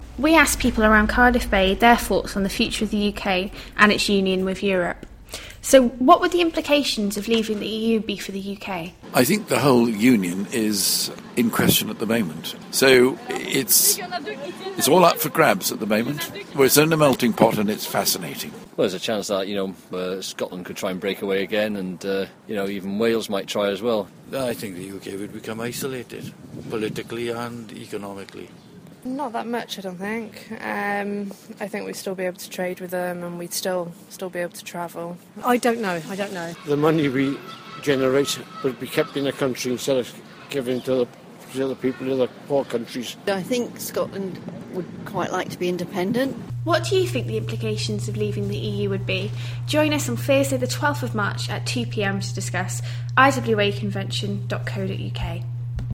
We asked people around Cardiff Bay for their views on what the implications for the UK would be if we left the EU.